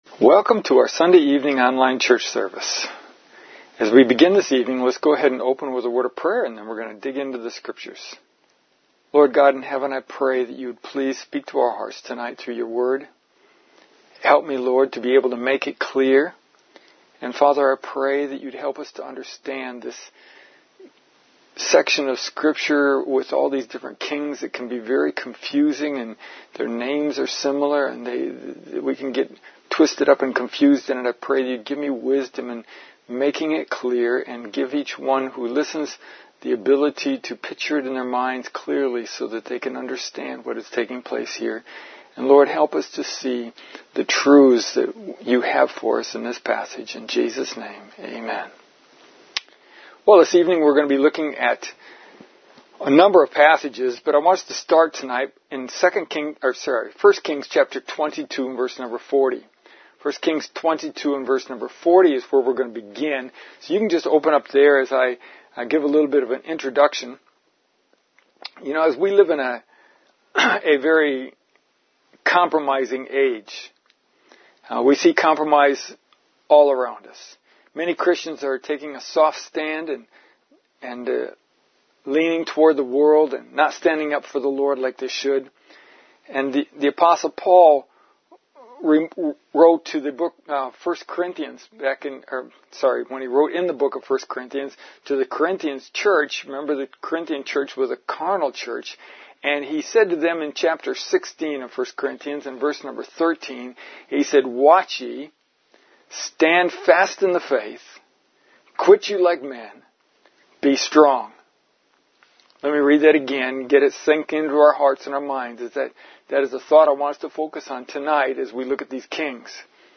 Our message this evening gives us three clear reasons to stand up for the Lord and refuse to compromise.